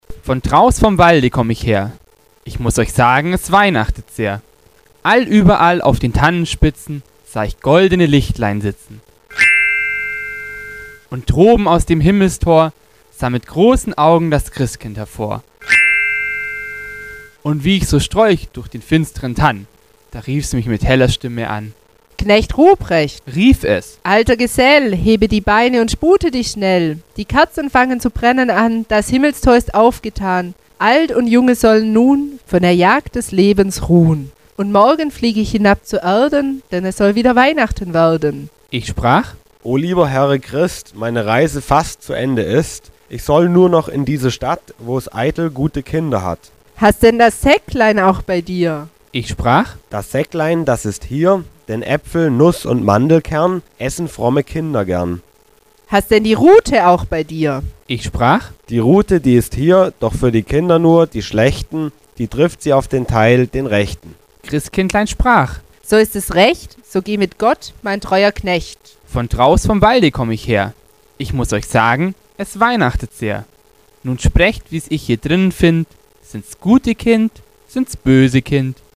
Kategorie 5: Gedichte